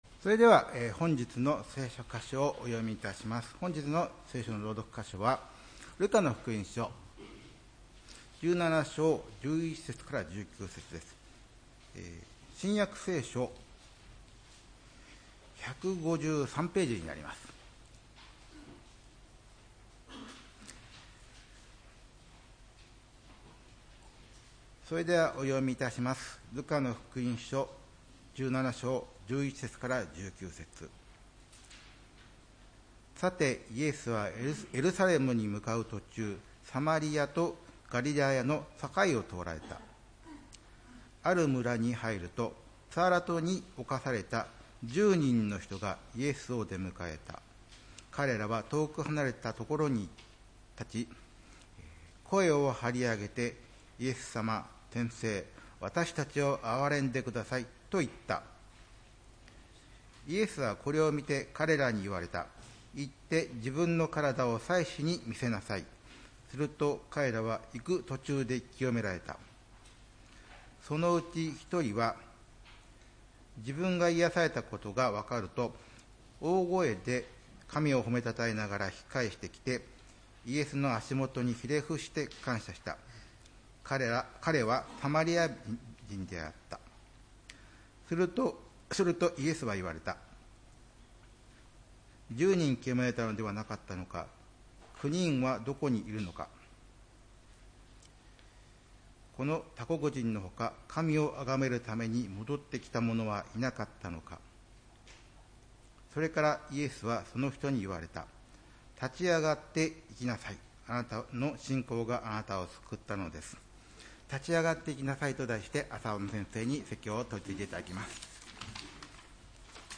礼拝メッセージ「立ち上がっていきなさい」（２月22日）